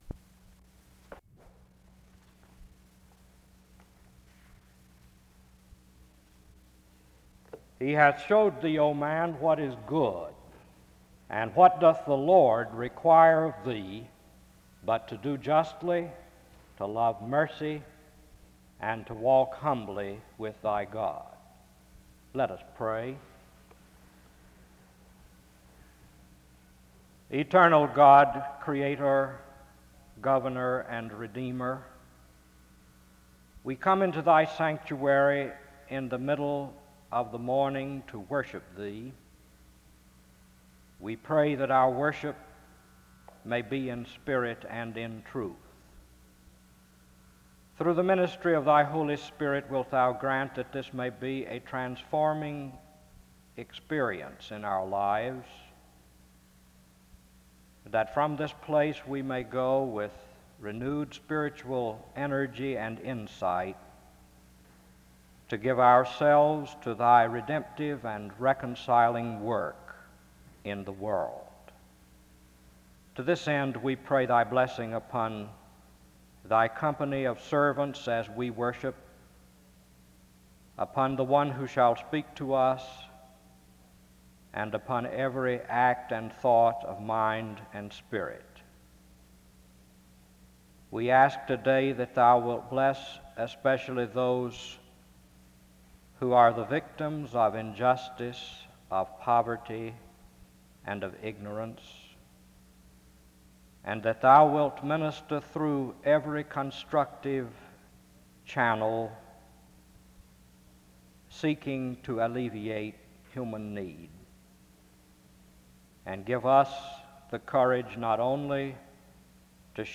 Download .mp3 Description An opening prayer was given after the reading of Micah 6:8 (0:07-02:20).